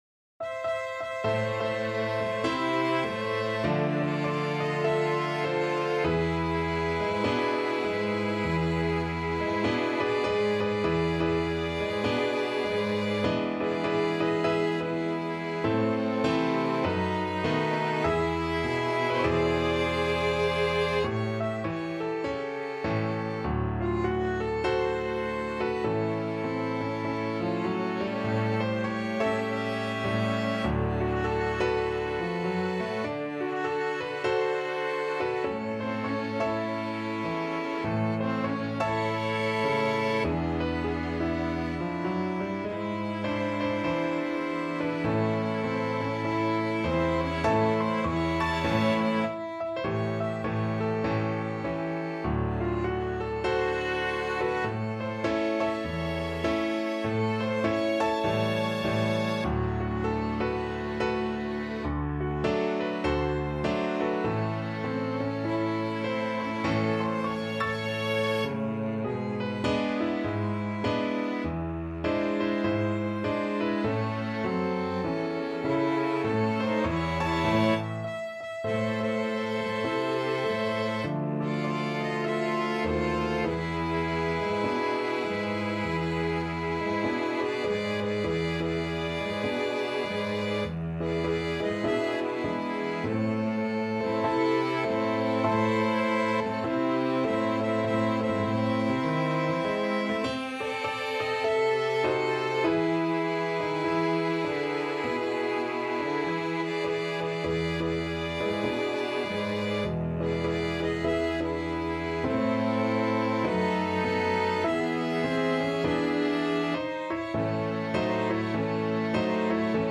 Violin 1Violin 2ViolaCelloPiano
4/4 (View more 4/4 Music)
With a swing =c.100
Piano Quintet  (View more Intermediate Piano Quintet Music)
Jazz (View more Jazz Piano Quintet Music)